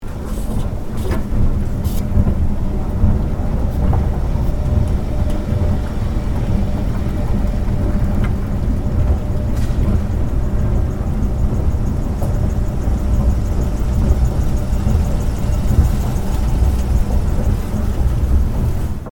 针对店铺自动门停下音效的PPT演示模板_风云办公